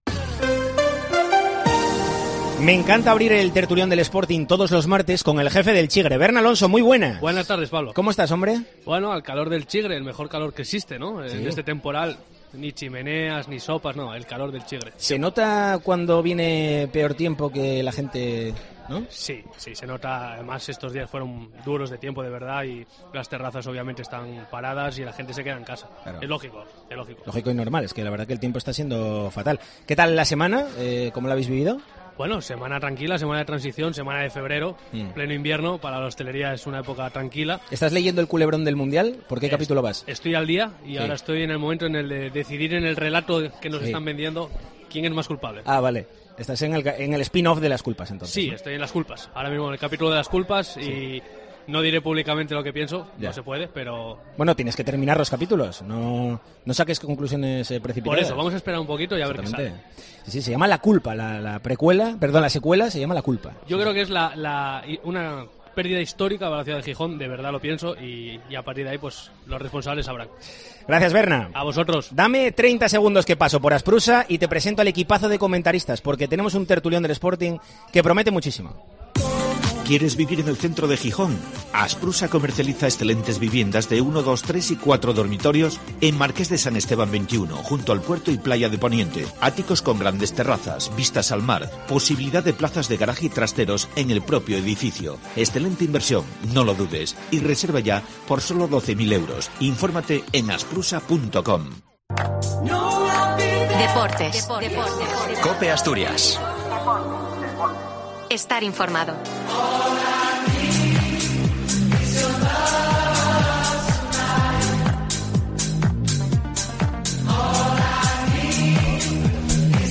'El Tertulión del Sporting', en COPE Asturias ¿Qué le ha pasado al Sporting para pasar de ser uno de los mejores equipos de la liga en el primer tercio a ser uno de los peores en este segundo tercio de campeonato? Lo debatimos en el capítulo de este martes de 'El Tertulión del Sporting' , desde La Cañada Real , en los bajos del estadio de El Molinón .